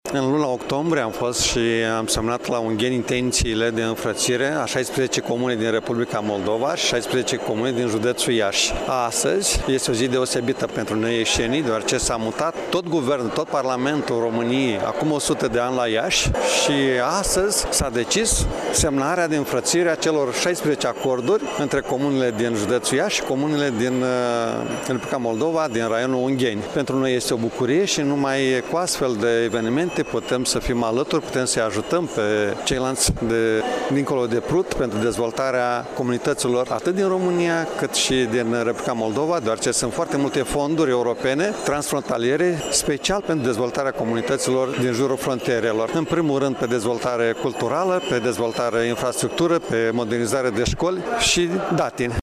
La 8 octombrie la Ungheni s-a desfăşurat Forumul Economic Regional România – Republica Moldova unde au fost puse bazele parteneriatului între cele 32 de primării, a amintit preşedintele Consiliului Judeţean Iaşi, Maricel Popa.